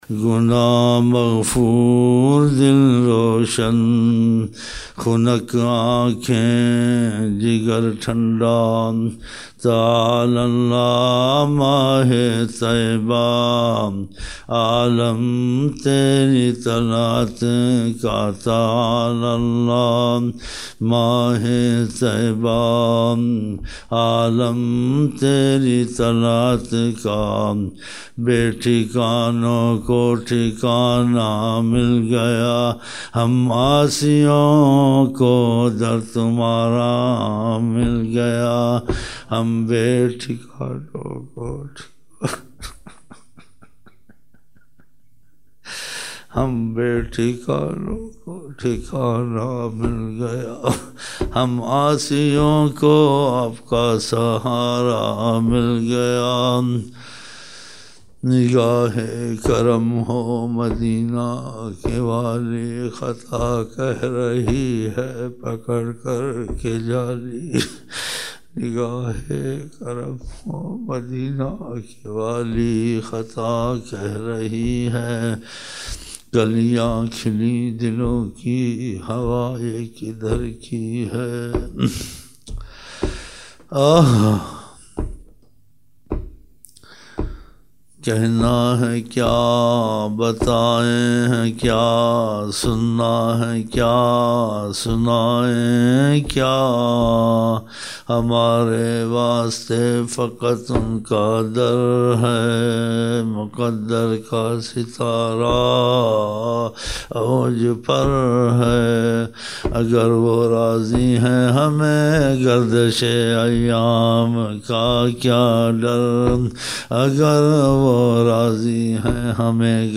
Gungunaye ashaar